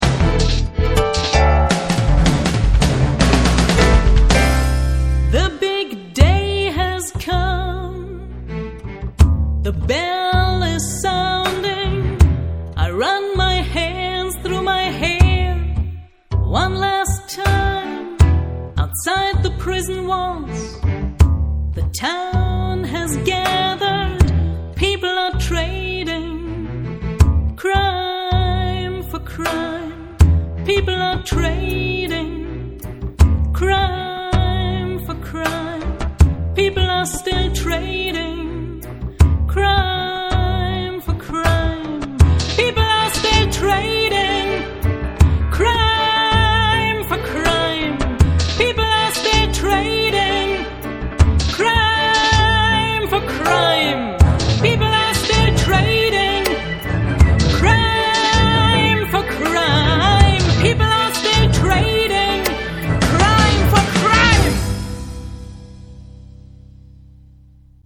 Gesang/Vocals
Piano, Keyboards